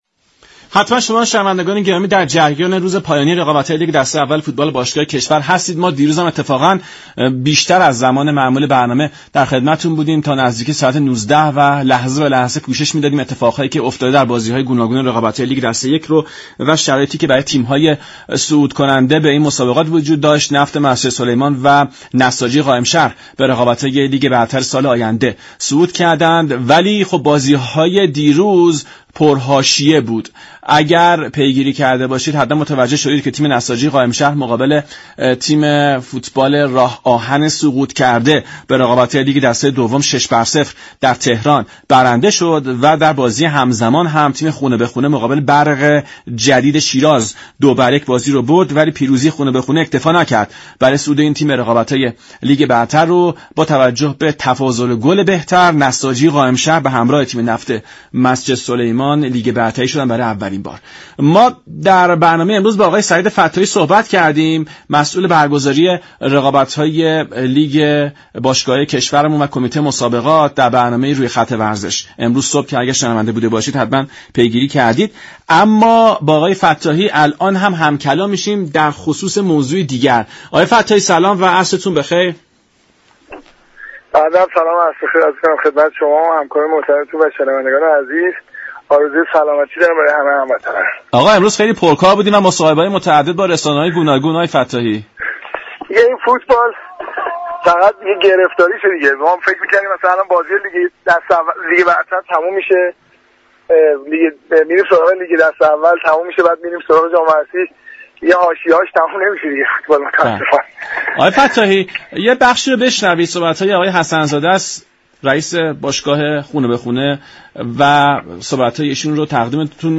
گفت و گو با برنامه «ورزش ایران»